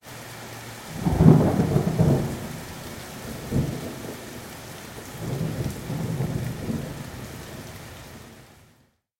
WEATHE R现场录音 " 00008 grzmot 8
描述：有雨的小风暴，高出路面50厘米，单声道，由AKG c414 TLII
Tag: 闪电 风暴